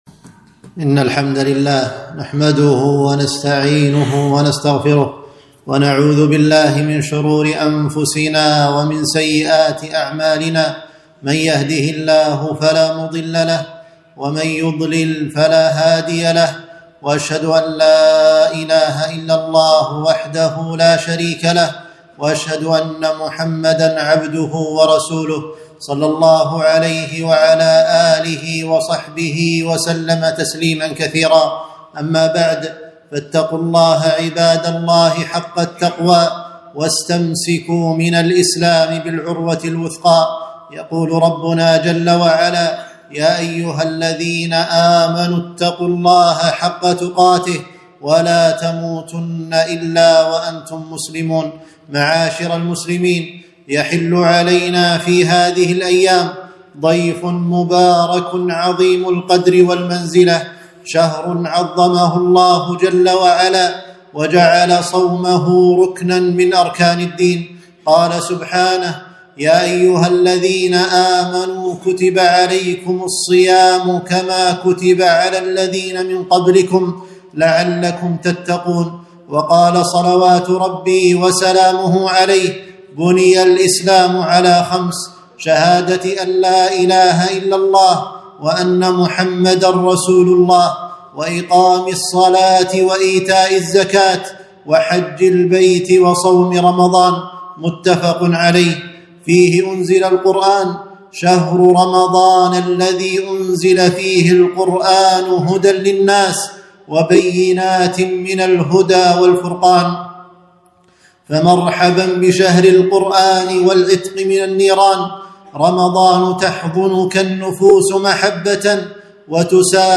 خطبة - مرحبًا شهر رمضان